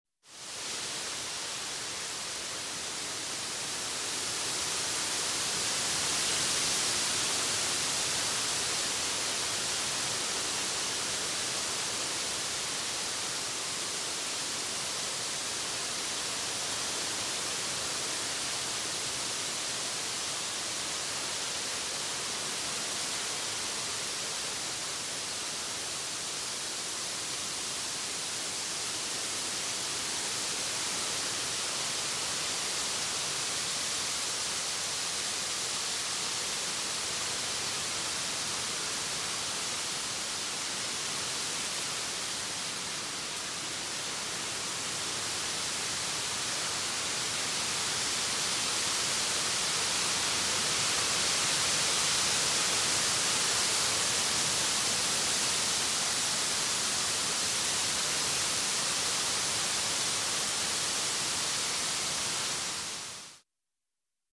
Звук ветра в лесу
Тихие ветры